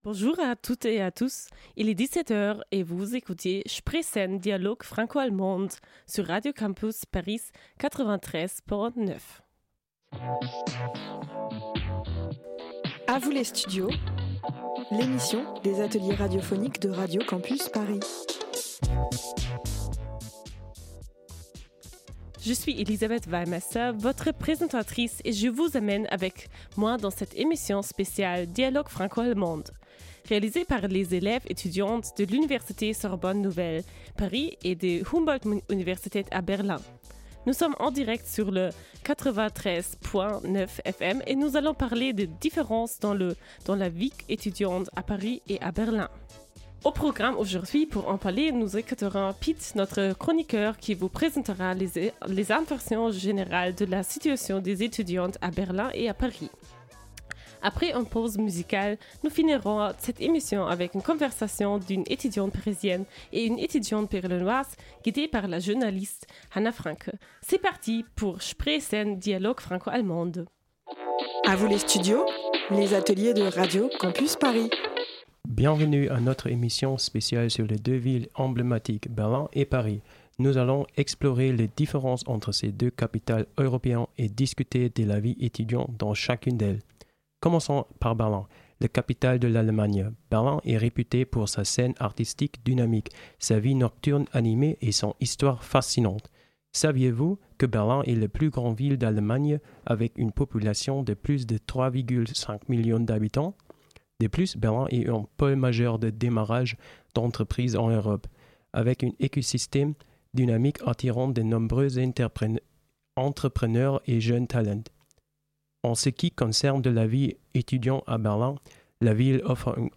Les étudiant·e·s du cursus franco-allemand de l'Université Paris 3 et de la Humboldt Universität vous proposent trois émissions inédites.